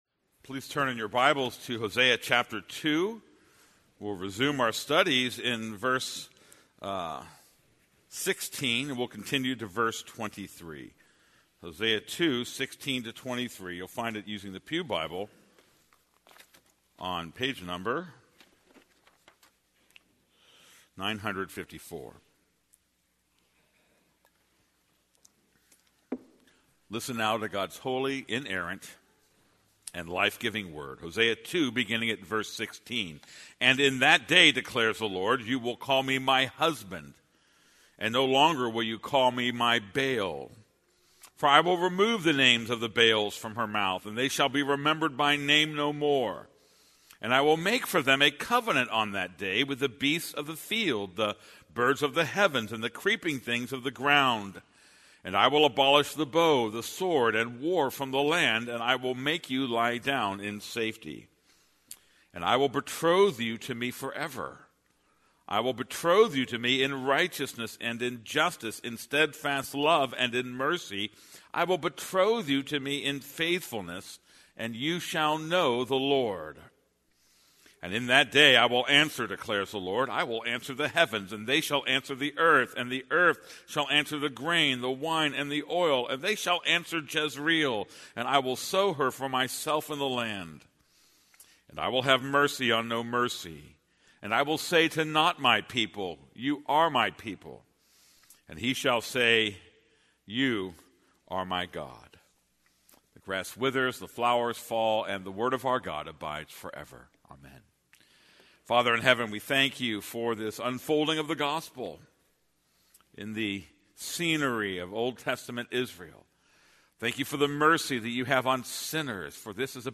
This is a sermon on Hosea 2:16-23.